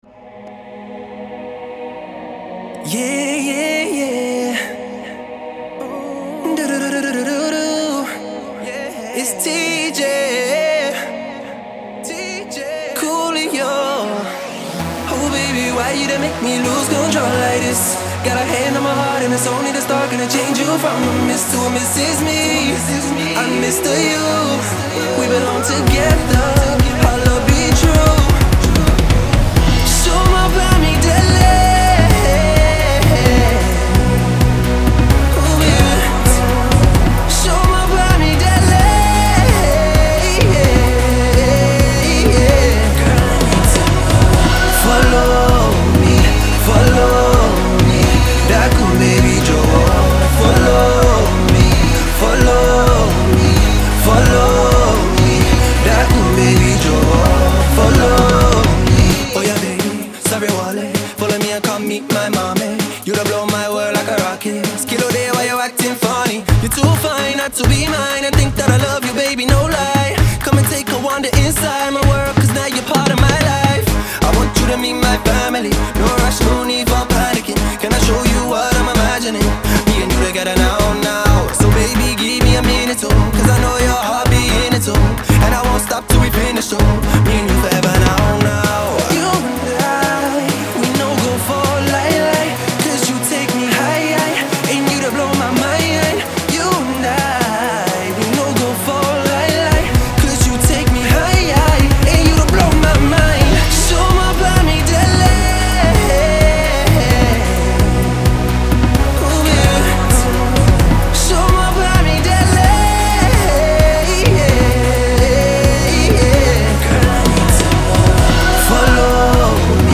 Blessed with a beautiful voice and excellent vocal skills